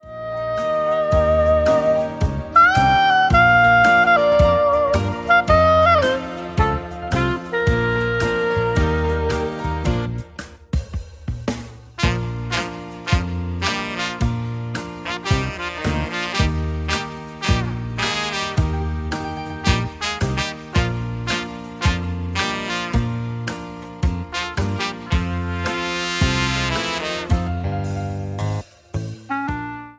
SHUFFLE  (03.36)